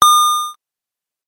昔の8ビット効果音です。